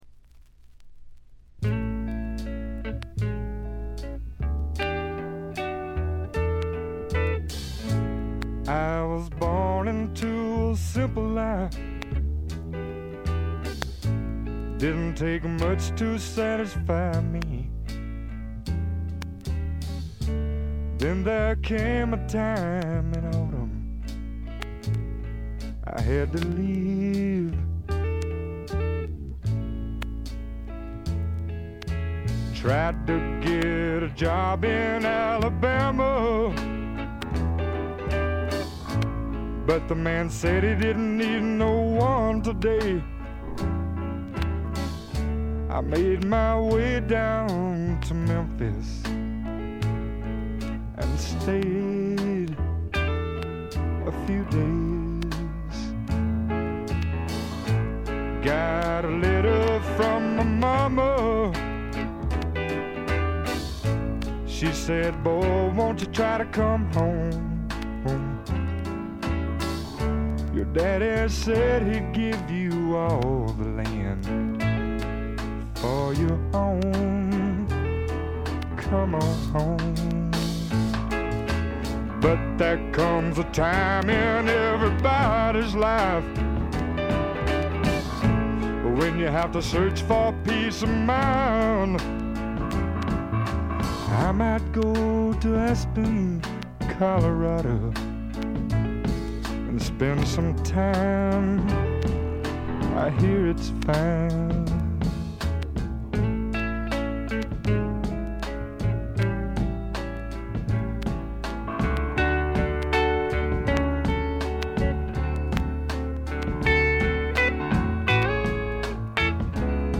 チリプチ、プツ音多め大きめ。
スワンプ基本！
試聴曲は現品からの取り込み音源です。
Guitar, Harmonica